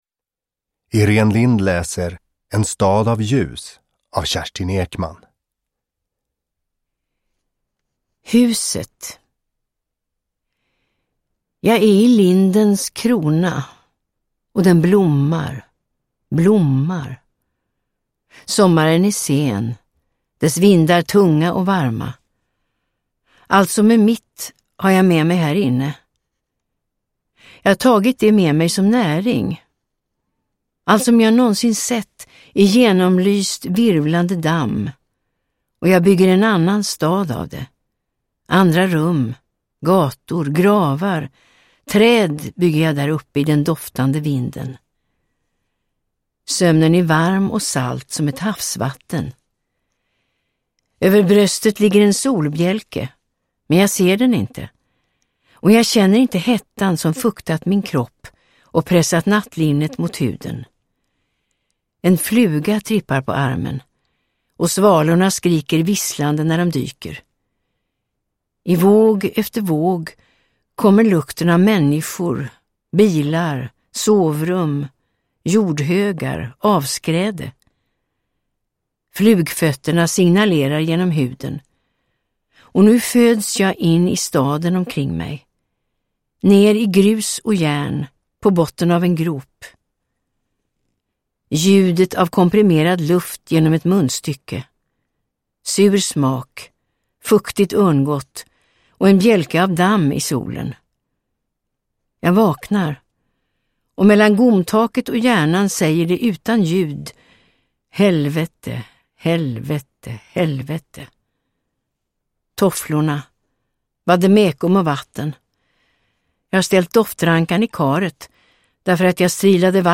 Uppläsare: Irene Lindh